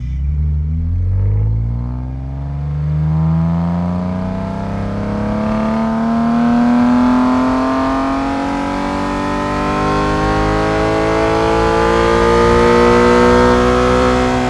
i6_01_Accel.wav